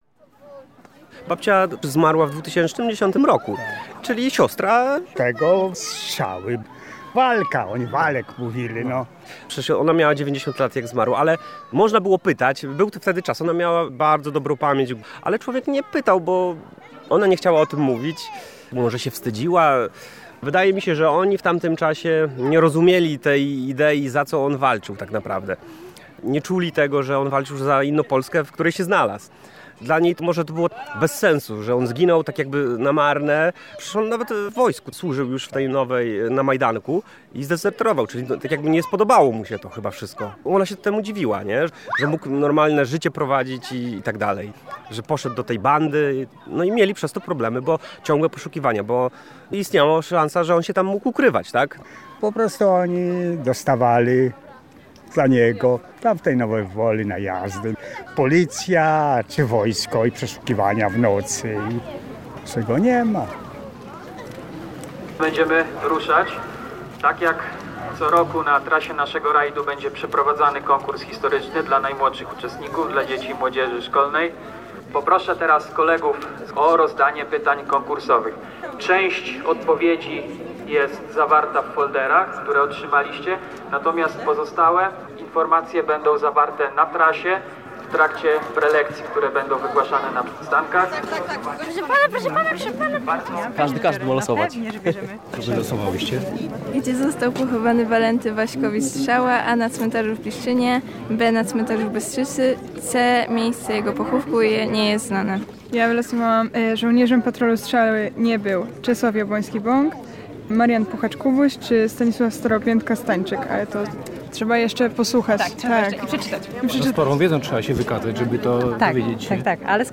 W reportażu wypowiadają się członkowie rodziny „Strzały” i jego żony, organizatorzy i uczestnicy rajdu a także świadek jego śmierci.